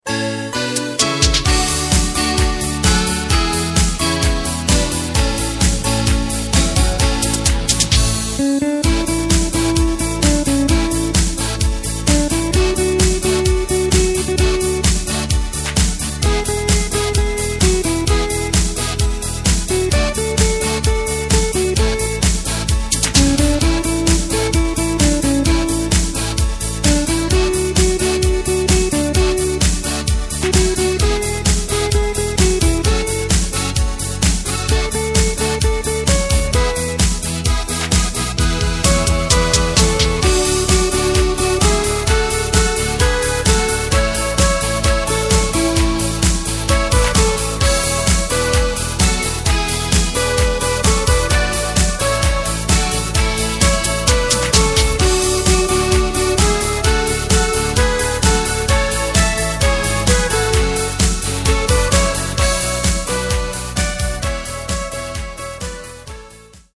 Takt:          4/4
Tempo:         130.00
Tonart:            A
Schlager aus dem Jahr 2007!